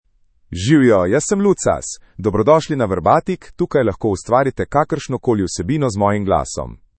Lucas — Male Slovenian AI voice
Lucas is a male AI voice for Slovenian (Slovenia).
Voice sample
Male
Lucas delivers clear pronunciation with authentic Slovenia Slovenian intonation, making your content sound professionally produced.